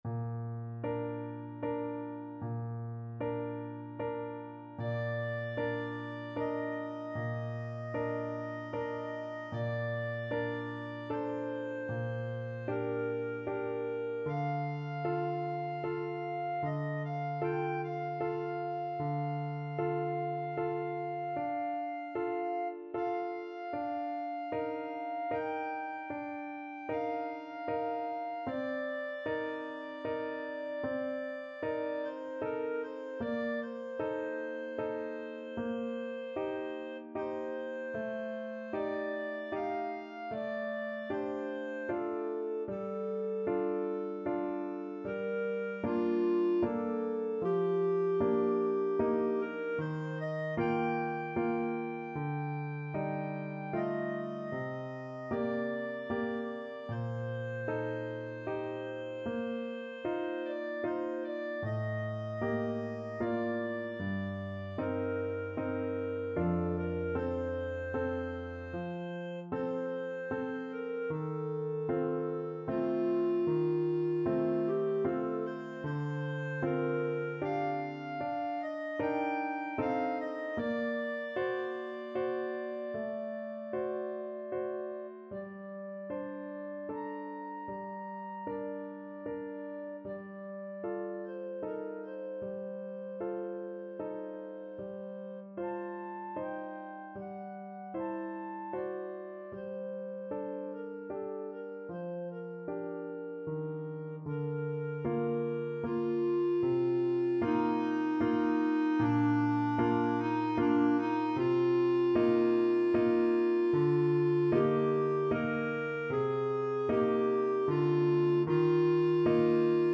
Clarinet
Bb major (Sounding Pitch) C major (Clarinet in Bb) (View more Bb major Music for Clarinet )
3/4 (View more 3/4 Music)
Adagio assai =76
Classical (View more Classical Clarinet Music)